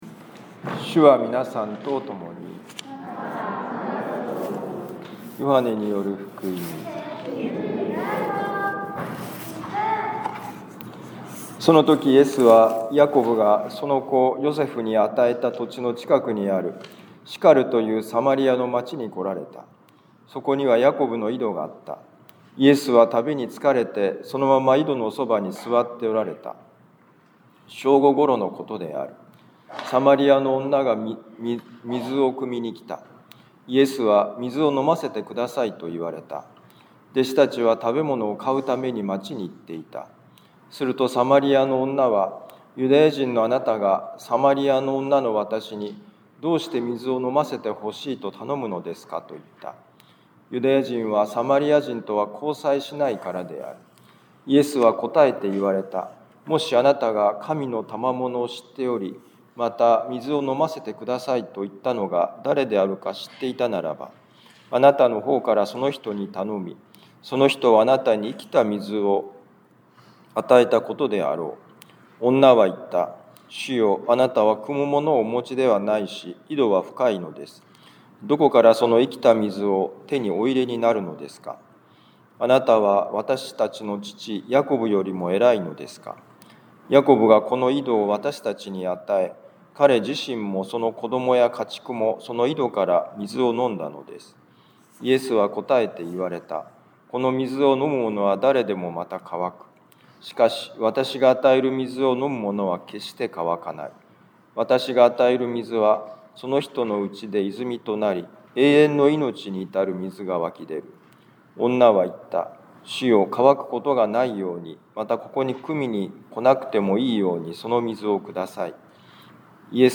ヨハネ福音書4章5-42節「心の穴」2026年3月８日四旬節第３主日ミサ防府カトリック教会